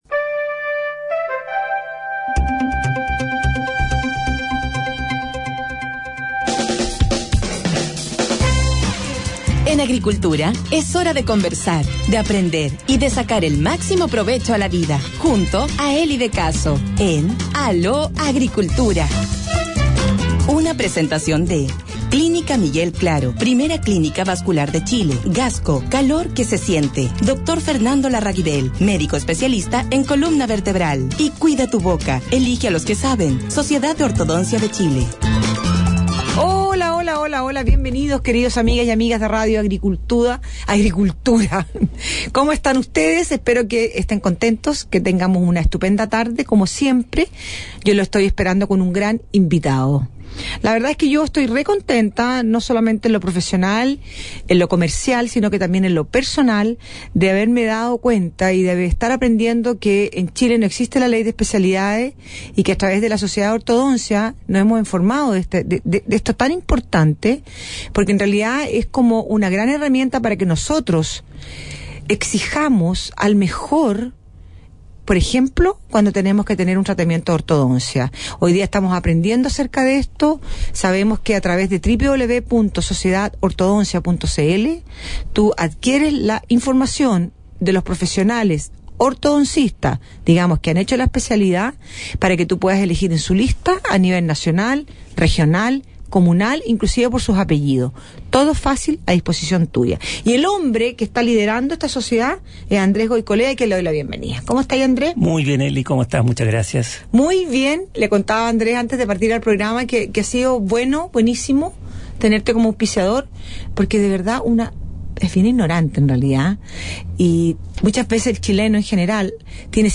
Sortchile | Entrevista Radio Agricultura 16 de Junio de 2016